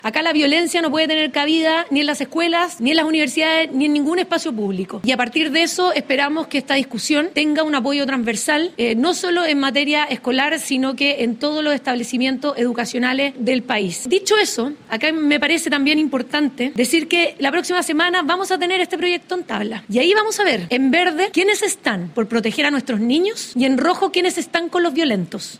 Vocería de la ministra Mara Sedini